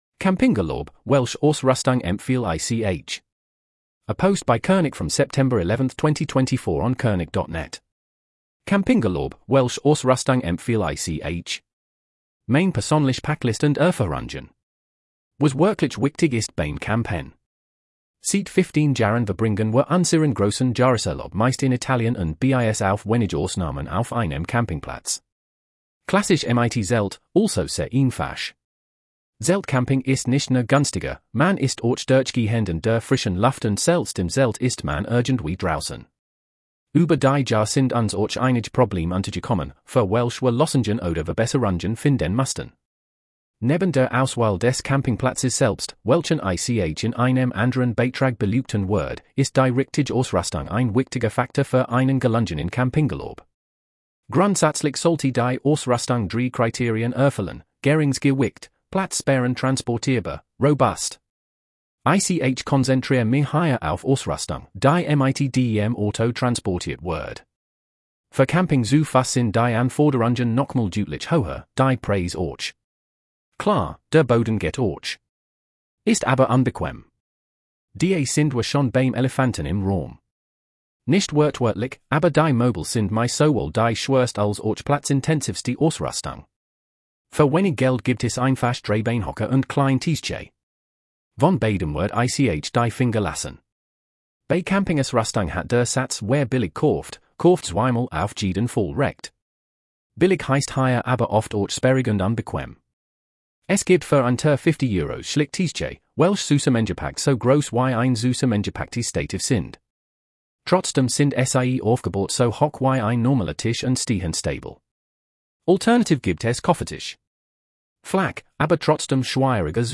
🔊 Read out blogpost